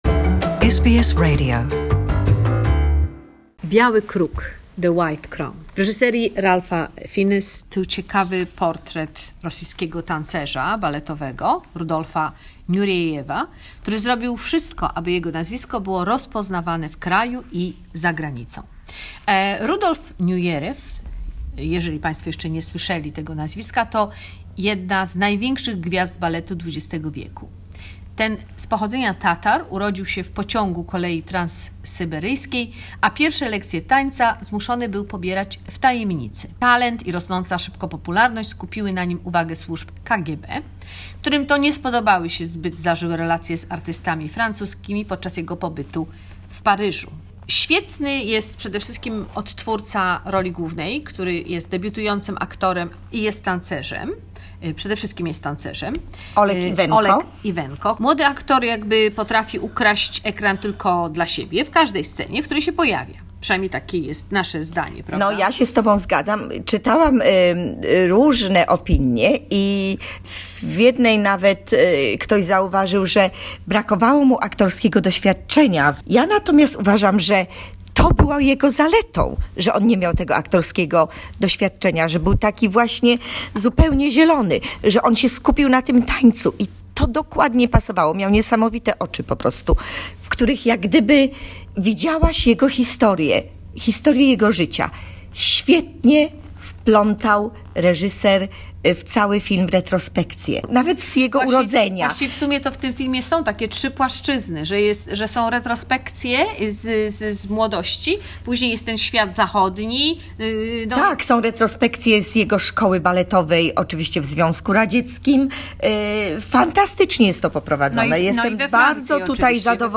Movie review: "White Crow"